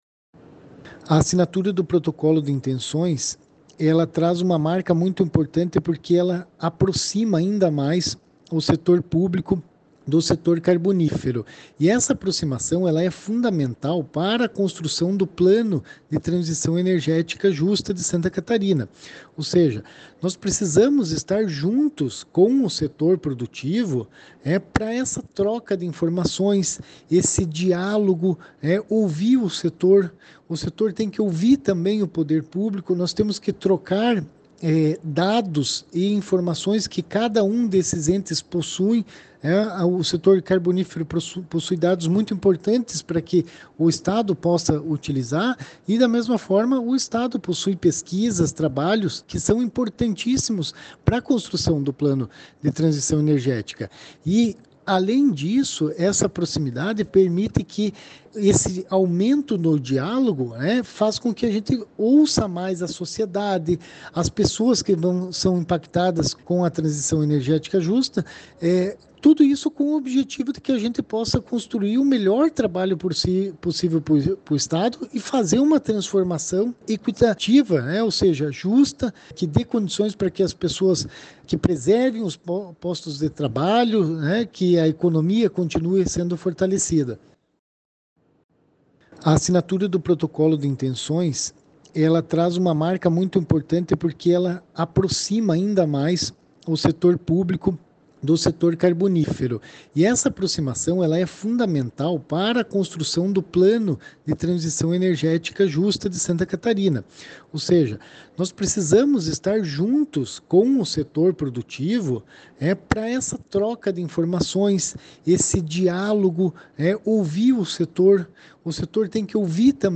O secretário de Estado do Meio Ambiente e da Economia Verde, Ricardo Guidi, comenta sobre o diálogo entre os setores para a construção do plano e fazer uma transformação justa:
O secretário adjunto de Meio Ambiente e Economia Verde, Guilherme Dallacosta, ressalta que a criação do modelo de negócios vai garantir a economia do sul, manutenção dos empregos e sustentabilidade:
ACN-Sonoras-Assinatura-protocolo-de-Transicao-Energetica.mp3